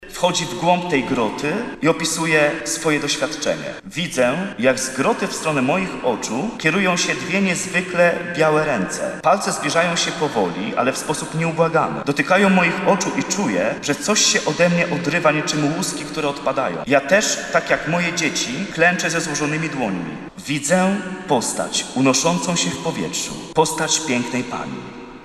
Wspólną modlitwę rozpoczęła msza święta w Bazylice Archikatedralnej św. Jana Chrzciciela na Starym Mieście.